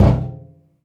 metal_drum_impact_thud_05.wav